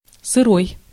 Ääntäminen
US : IPA : [ˈruːd]